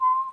hover.ogg